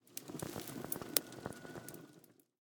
Minecraft Version Minecraft Version latest Latest Release | Latest Snapshot latest / assets / minecraft / sounds / block / vault / ambient1.ogg Compare With Compare With Latest Release | Latest Snapshot